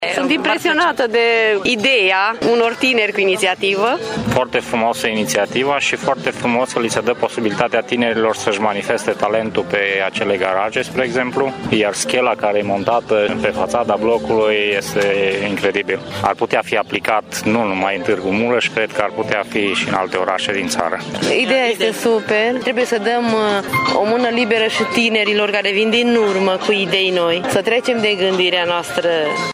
Târgumureșenii sunt impresionați de viziunea tinerilor de a transforma blocurile comuniste și sunt deschiși la transformări: